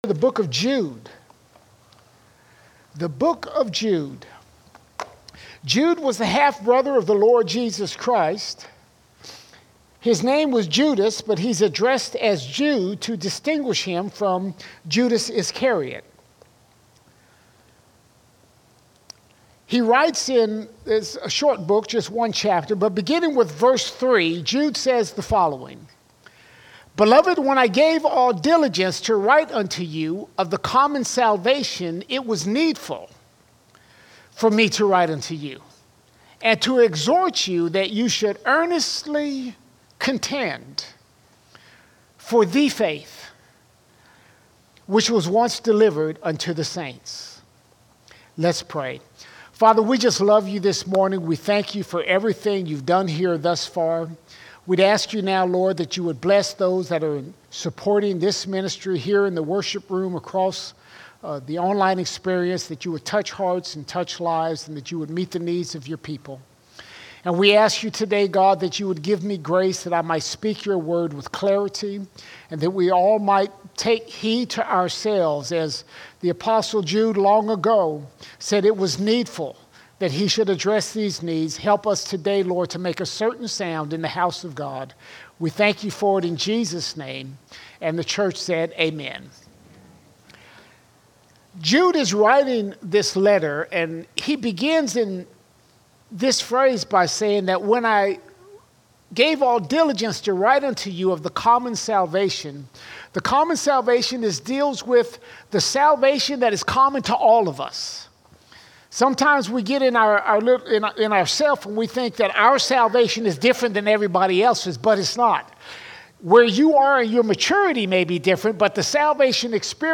19 August 2024 Series: Sunday Sermons Topic: false church All Sermons The Faith's Adversary The Faith’s Adversary Many churches are no longer preaching the Truth of God's Word.